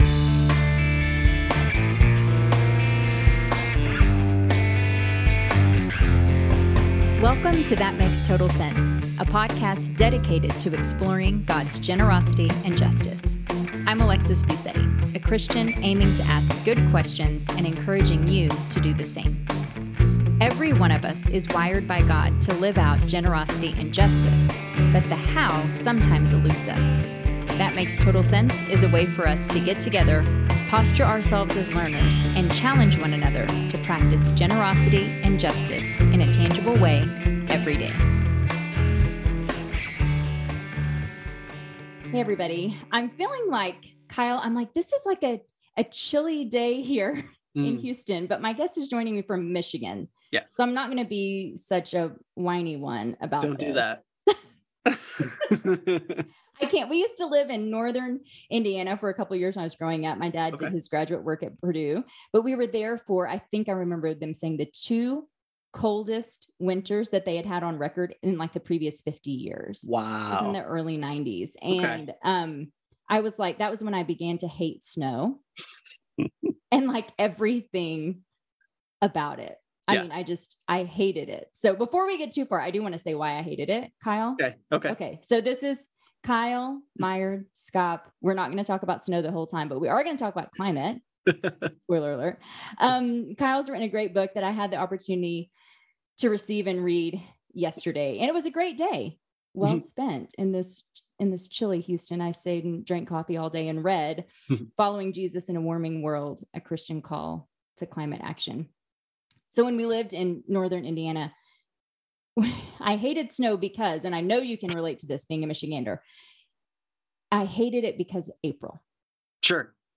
A conversation on climate change straight from Texas!